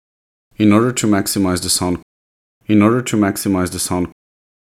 Problem with my Ssss (sample recording included)
They’re too sharp, or to loud, I don’t know the exact term but they’re clearly annoying to the listener.
I’m using a condenser mic with a pop filter, and I’m about 10min away from the microphone.
To remove this sss (sibilance) is called de-essing.
BTW2 you are too close to the mic which is causing some distortion.